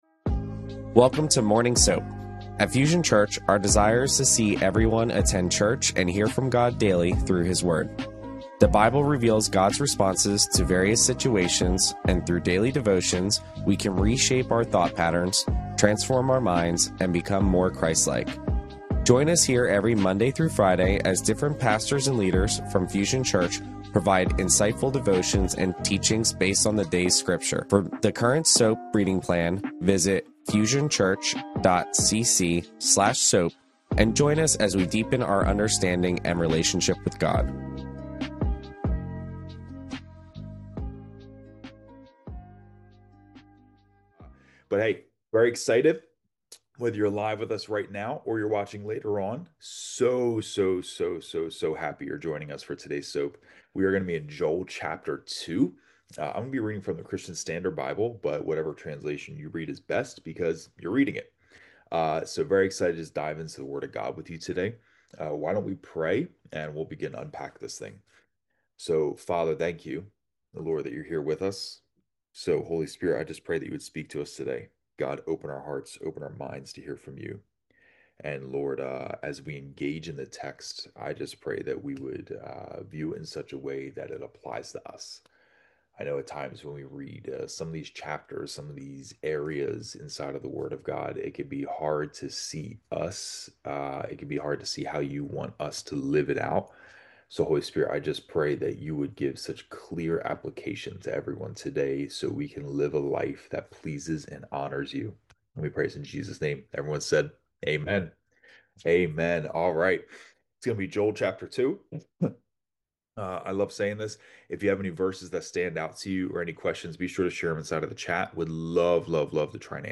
Fusion Church Morning SOAP Bible Study Thursday